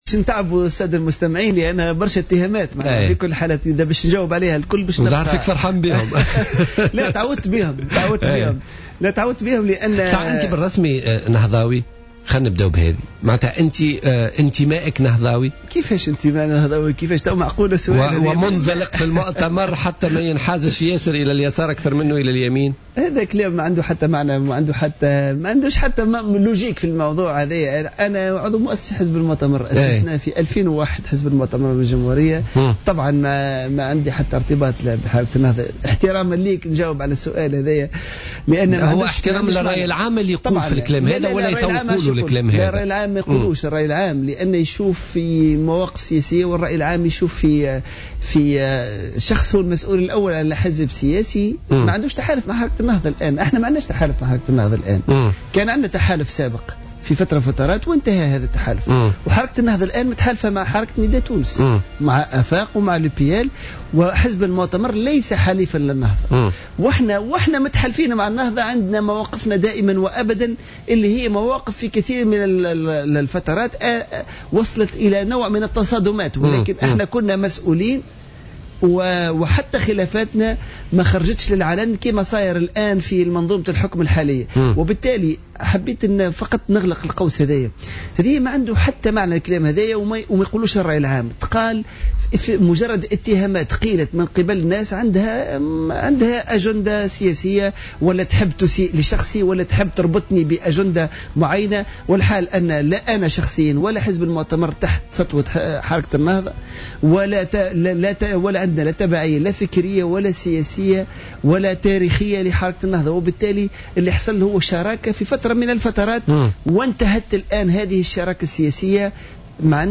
Le député à l’assemblée des représentants du peuple du CPR Imed Daimi a nié ce mardi 10 mars 2015 lors de son passage sur les ondes de Jawhara FM dans le cadre de l’émission Politica, les informations récemment propagées indiquant son appartenance au mouvement Ennahdha.